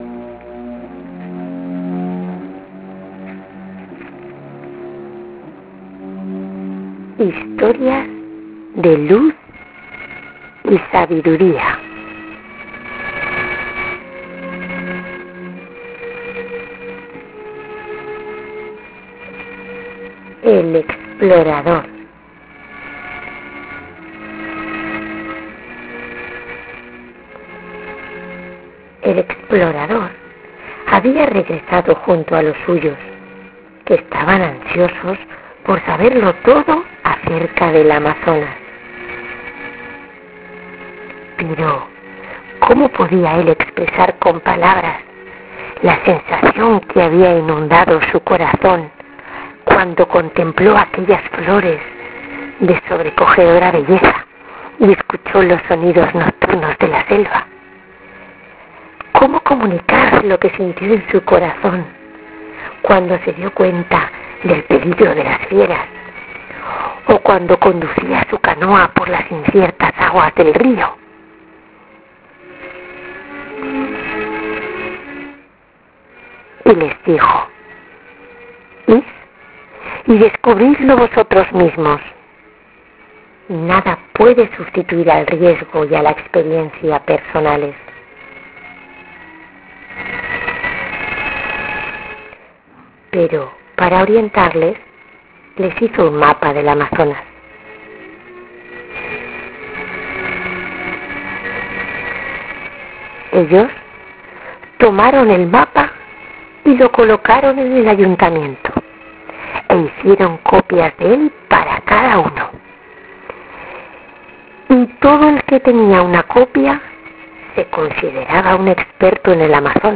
Ahora puedes bajarte esta historia narrada (733 kb)